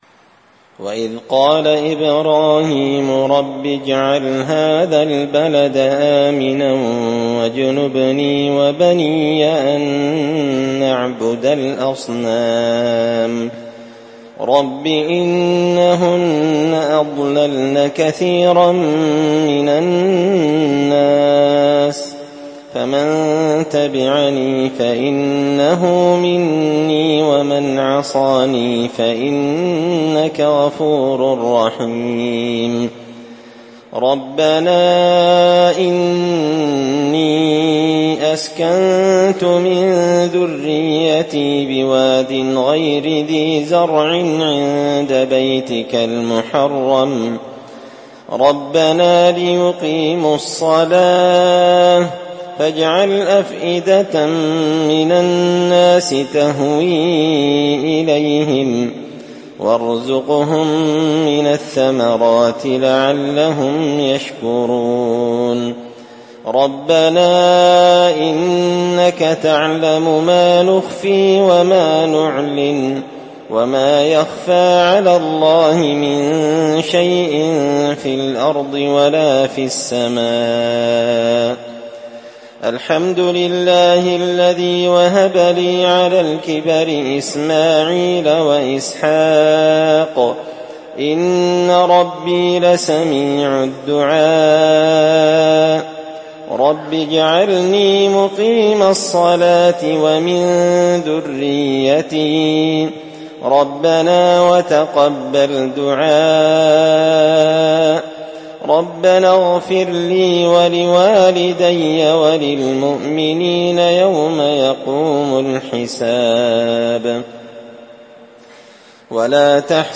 تلاوة من سورة إبراهيم
الأربعاء 11 ذو القعدة 1444 هــــ | قران كريم | شارك بتعليقك | 13 المشاهدات
تلاوة-من-سورة-إبراهيم.mp3